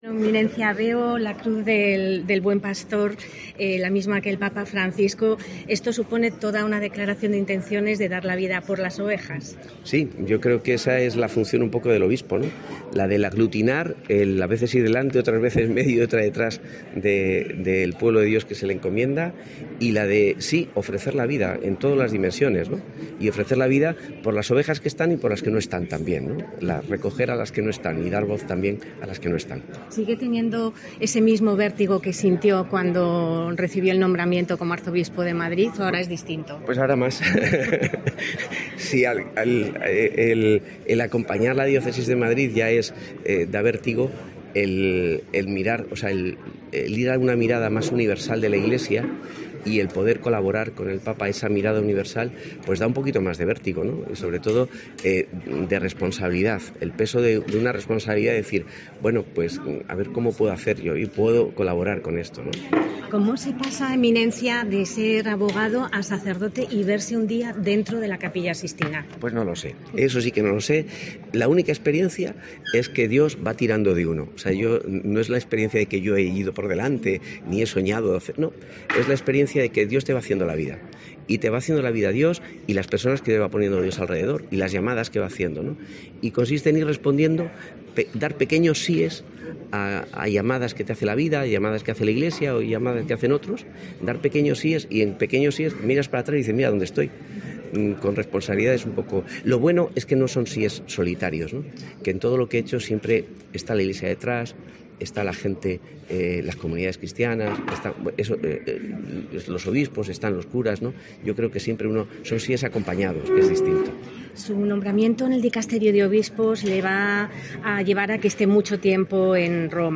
Entrevista José Cobo